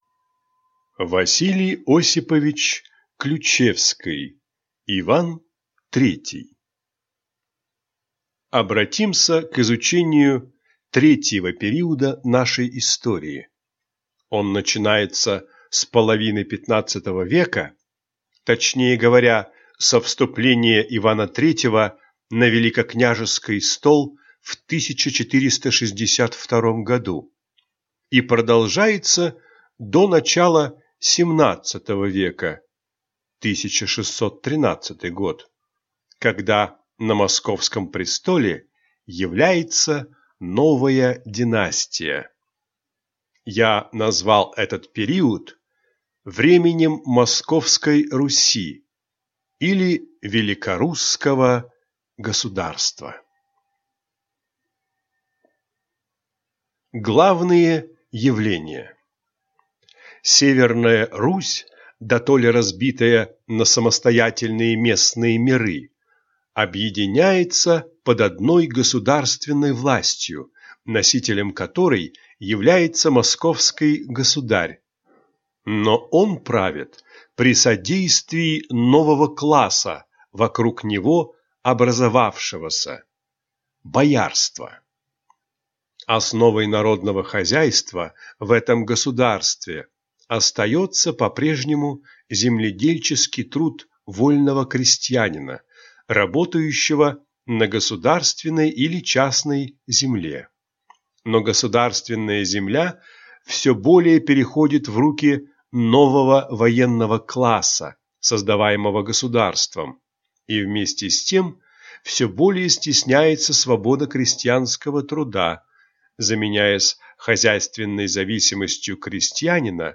Аудиокнига Иван III | Библиотека аудиокниг
Прослушать и бесплатно скачать фрагмент аудиокниги